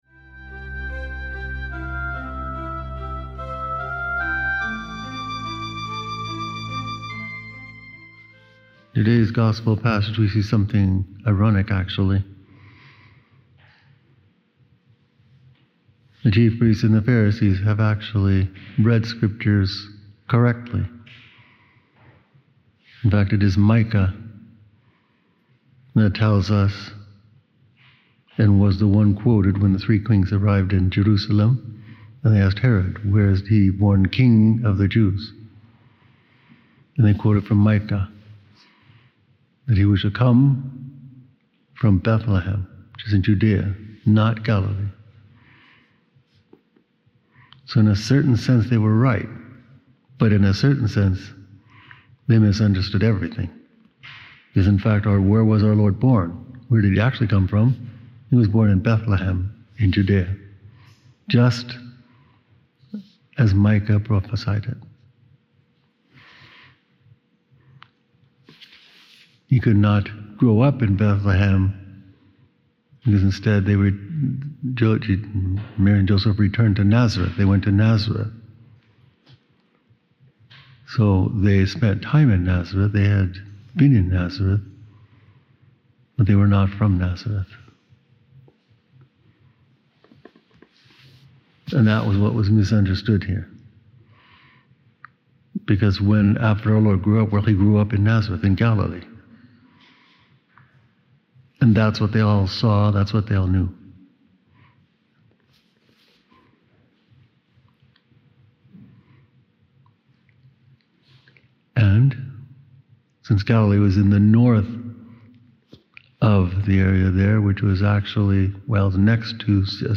Jesus Fulfills All Old Testament Messianic Prophecies- Mar 21 – Homily